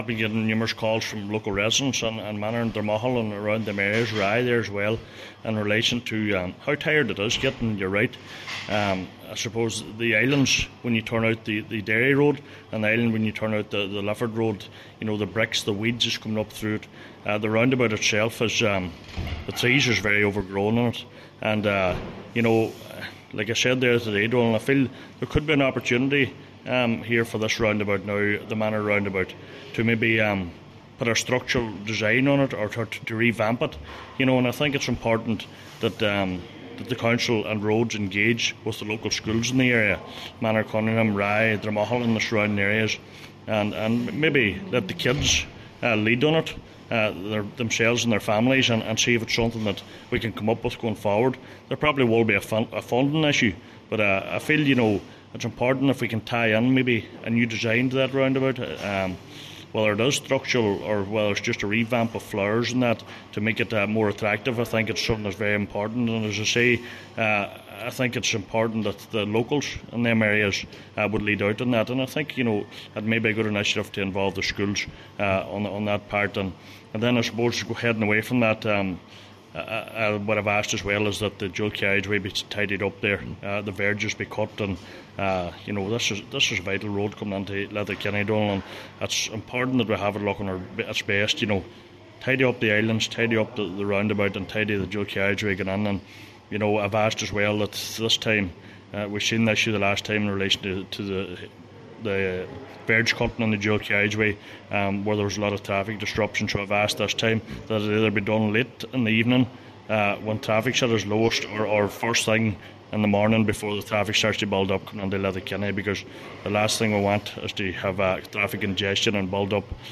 The call came at a meeting this week from Cllr Donal Mandy Kelly.
Cllr Kelly says some form of structure or artwork should be considered for the Manor Roundabout, with involvement from the local community: